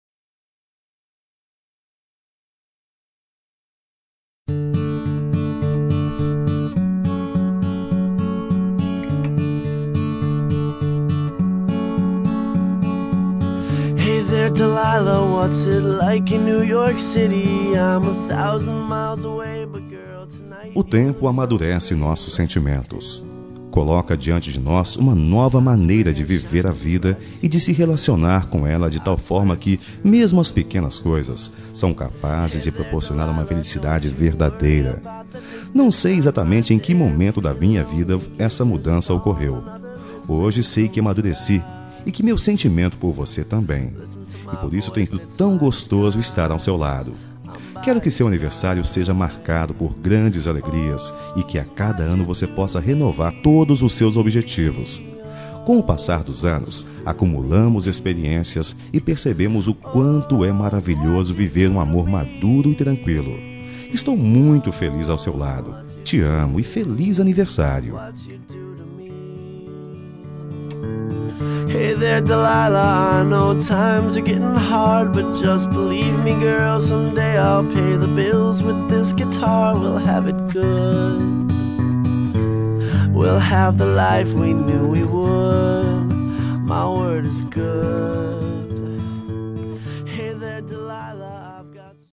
Voz Masculina
Código: 0022 – Música: Hey There Delilah – Artista: Plain White Ts